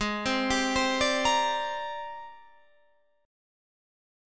AbM#11 chord